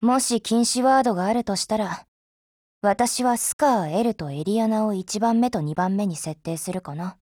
SCARH_DIALOGUE1_JP.wav